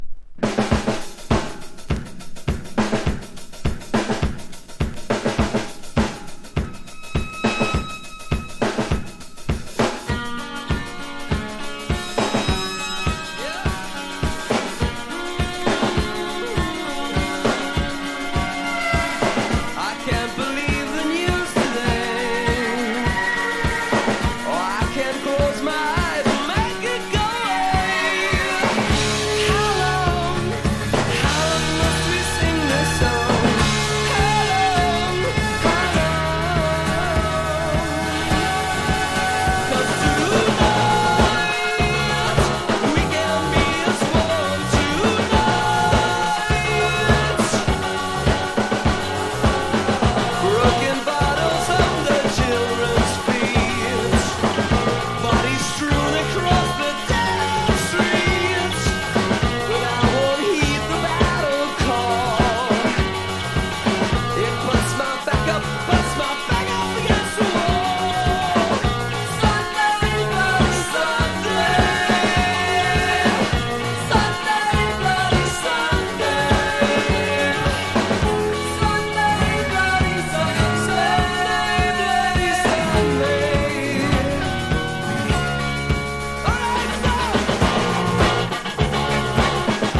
1. NEW WAVE >